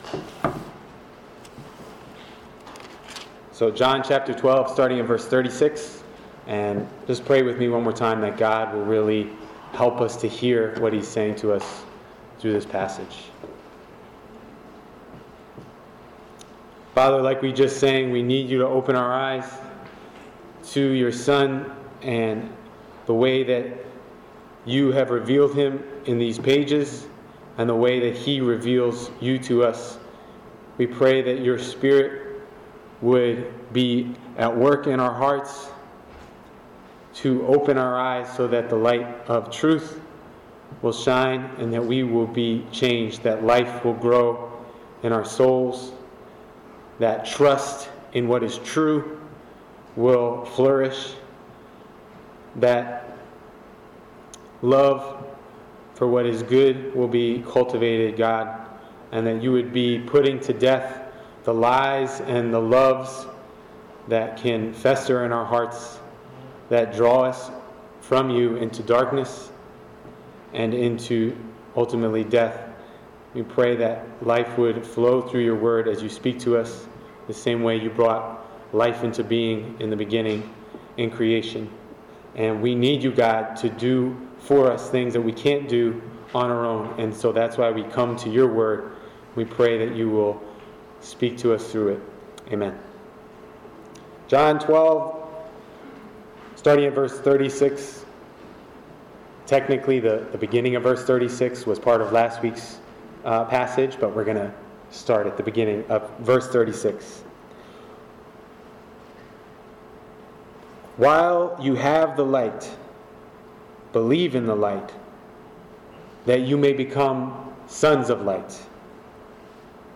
Marriage, Singleness and Dating Seminar Part 2: Singleness